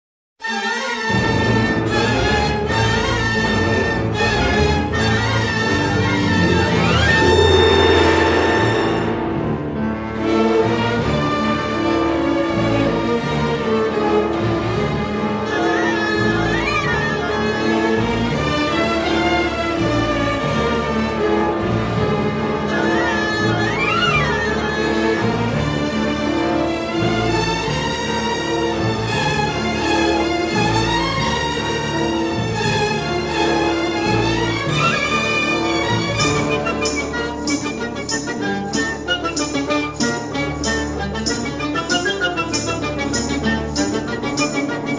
with harmonic-minor twists both sinister and mysterious.